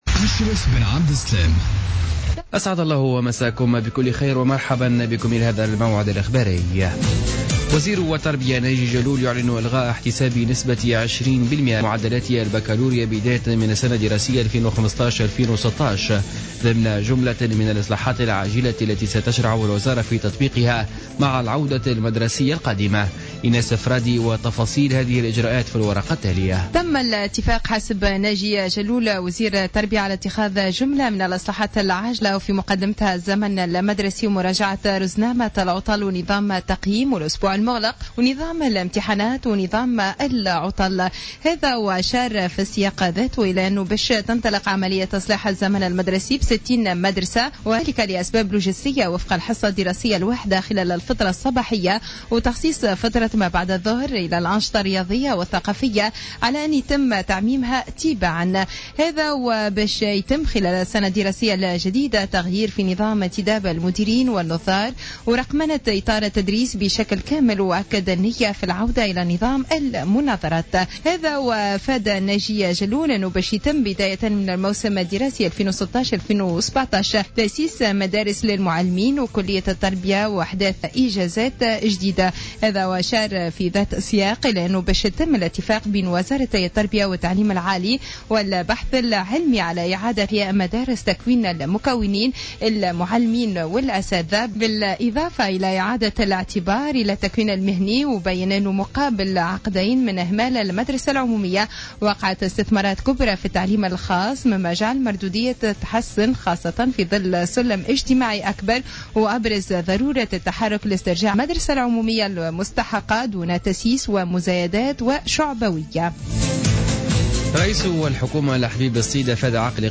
نشرة أخبار السابعة مساء ليوم الأربعاء 12 أوت 2015